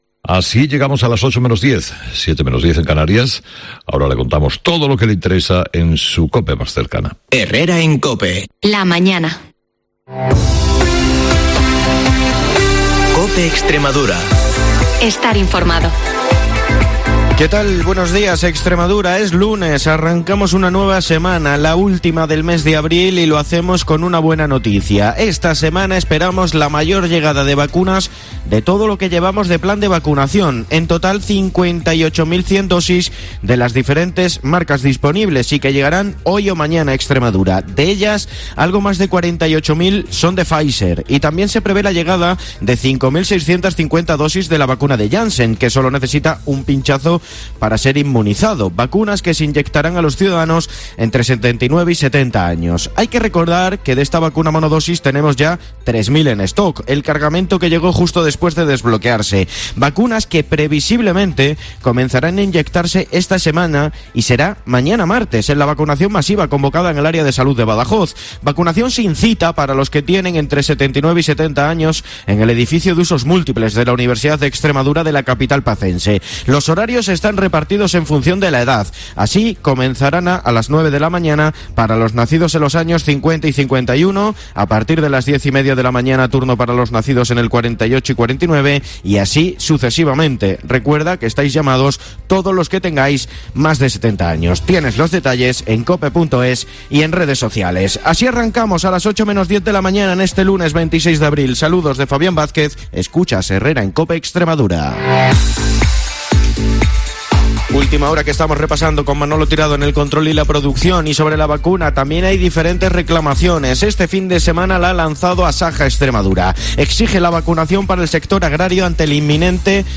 el informativo líder de la radio en la región